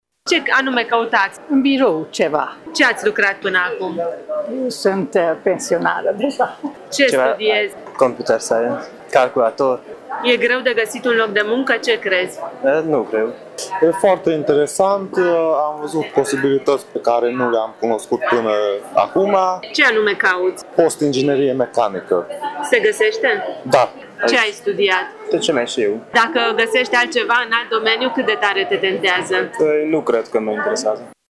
Târgul a trezit interesul mureșenilor de toate vârstele, de la liceeni preocupați de internshipuri și voluntariat, până la pensionari dornici să mai lucreze: